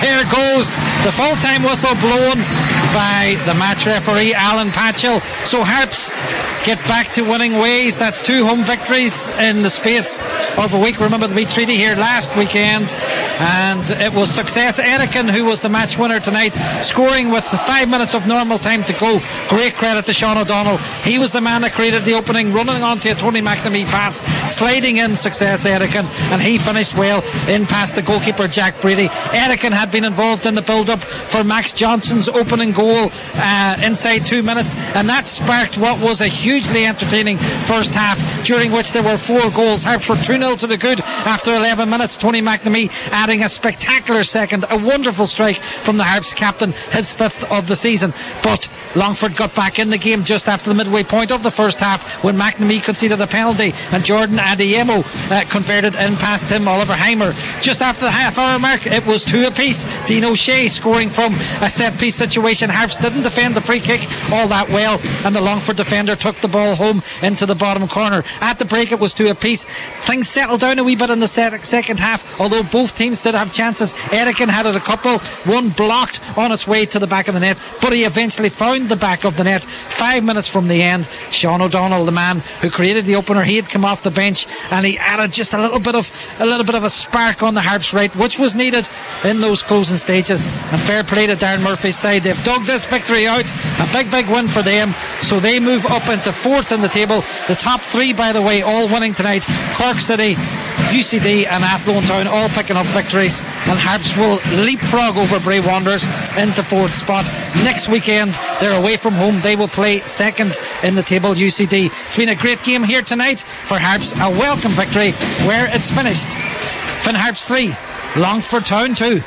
reported live from Ballybofey at full time…